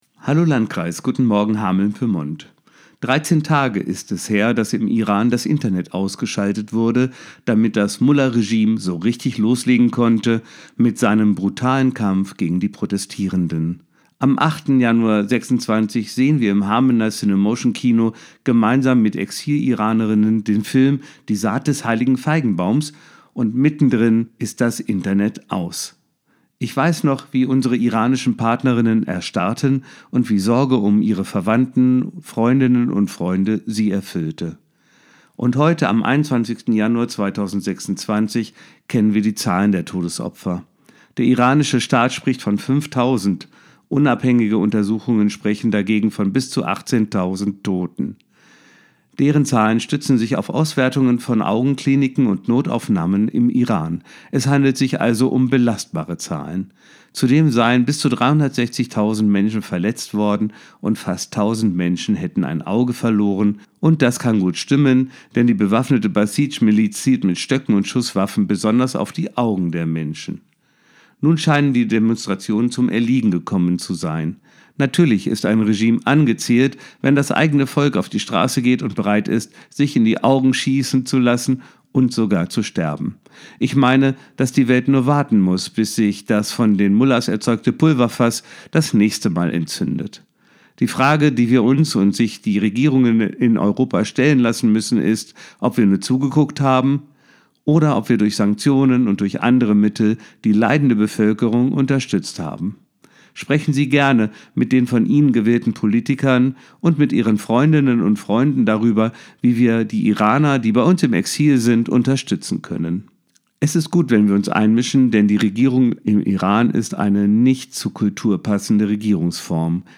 Radioandacht vom 21. Januar